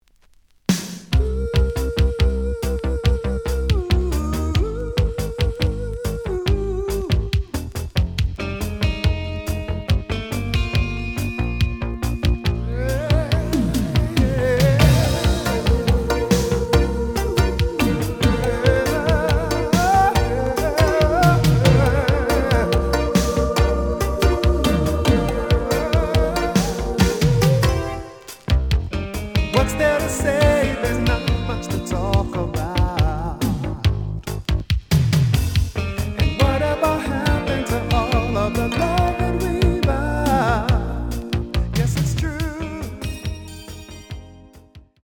The audio sample is recorded from the actual item.
●Genre: Soul, 80's / 90's Soul
Slight edge warp. But doesn't affect playing. Plays good.